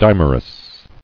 [dim·er·ous]